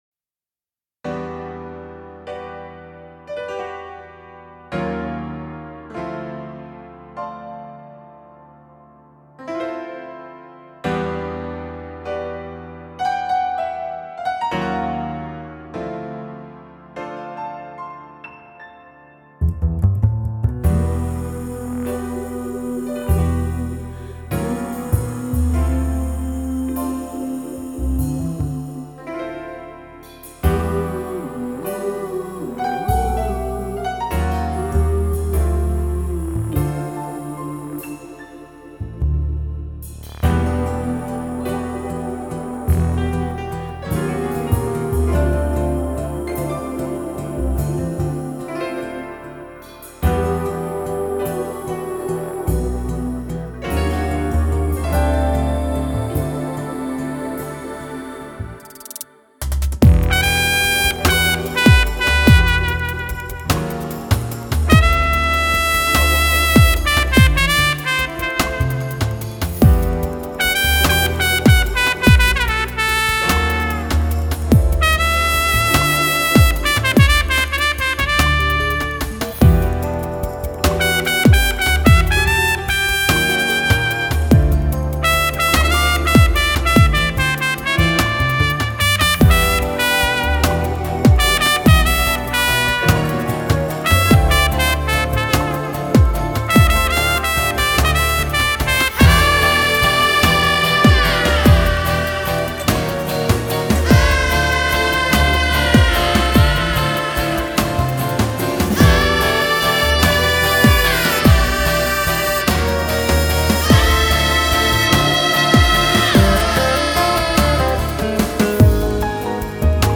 [Artist: Instrumental ]
Bollywood Mp3 Music